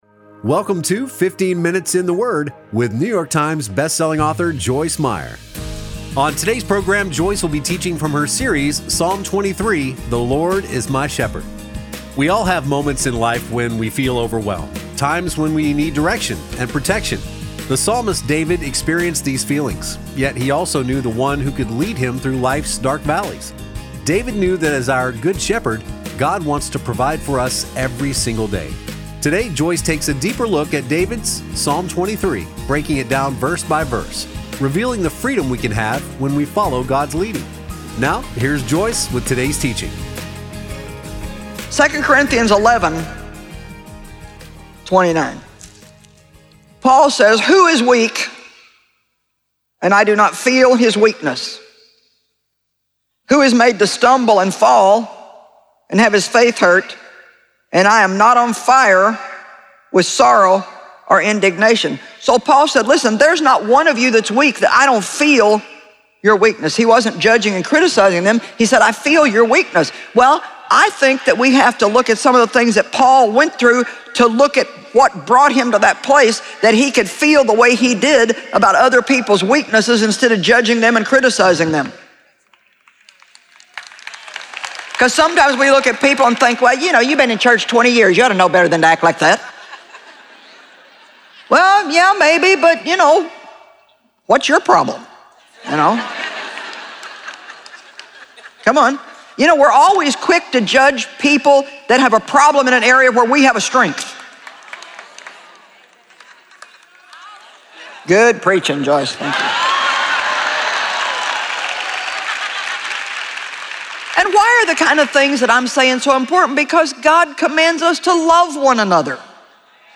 Joyce Meyer teaches on a number of topics with a particular focus on the mind, mouth, moods and attitudes. Her candid communication style allows her to share openly and practically about her experiences so others can apply what she has learned to their lives.